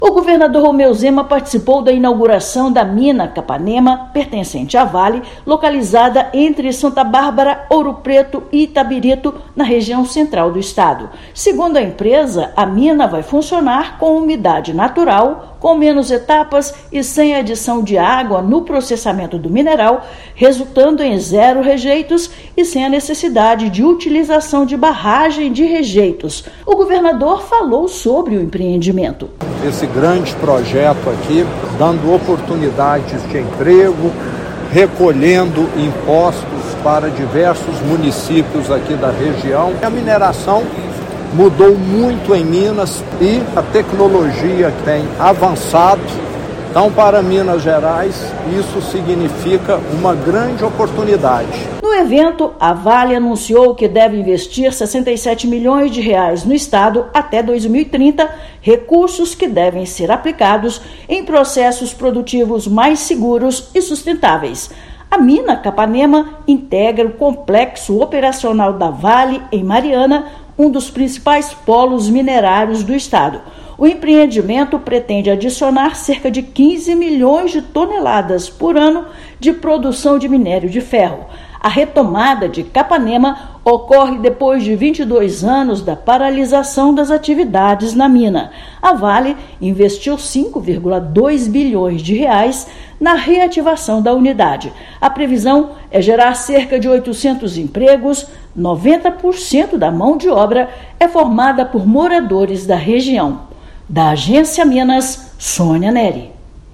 Na fase de operação, unidade da Vale deve gerar cerca de 800 empregos na região. Ouça matéria de rádio.